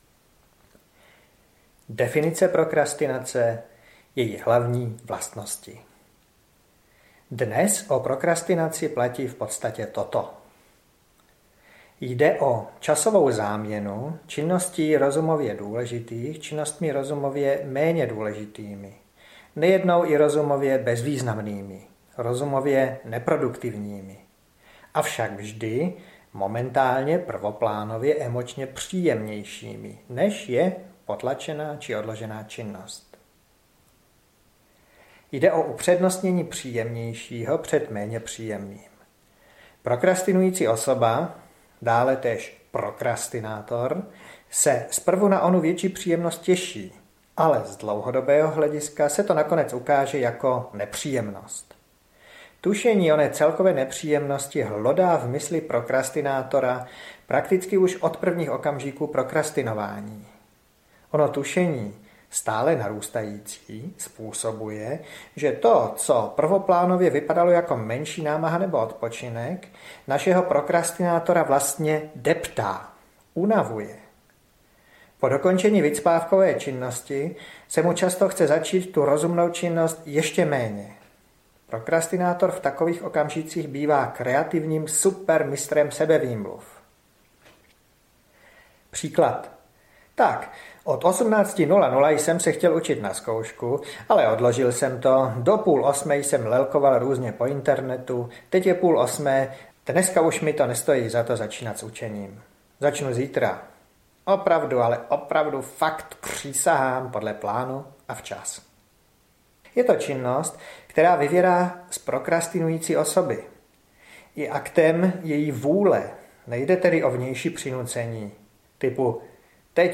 Prokrastinace - odklad, který mrzí… audiokniha
Ukázka z knihy